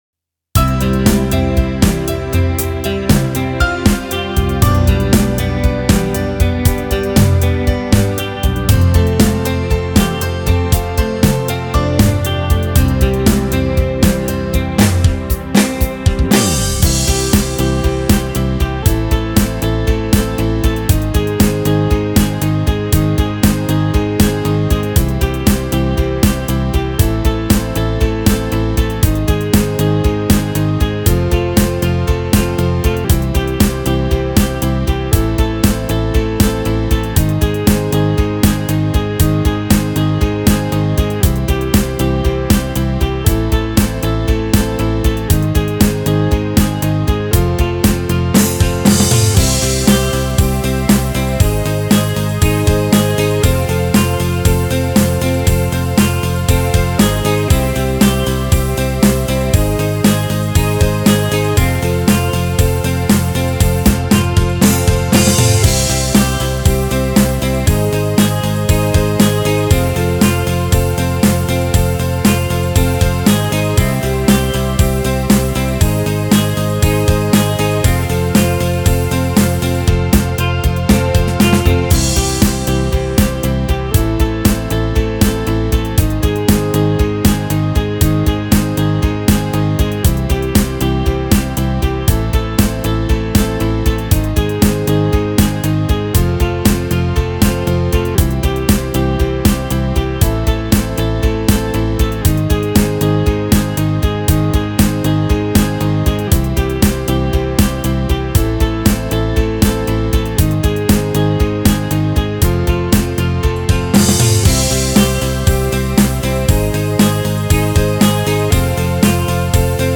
BritRock BPM 118